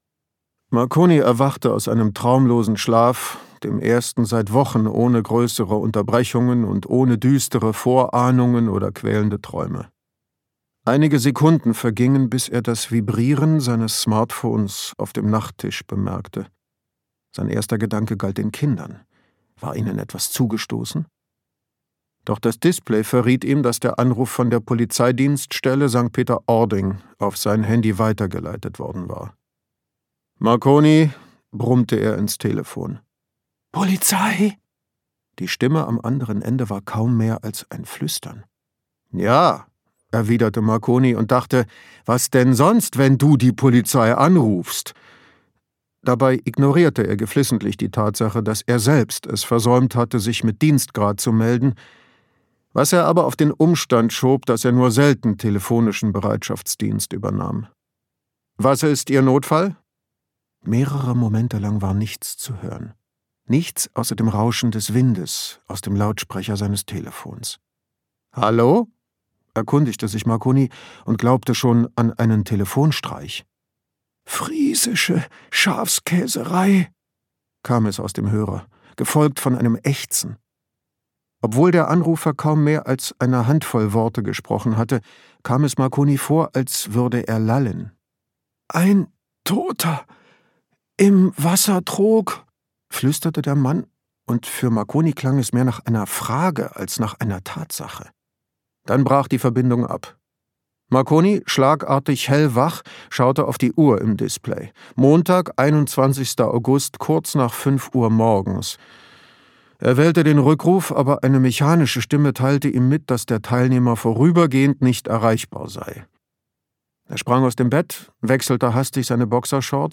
Marconi und die schweigenden Lämmer - Daniele Palu | argon hörbuch
Gekürzt Autorisierte, d.h. von Autor:innen und / oder Verlagen freigegebene, bearbeitete Fassung.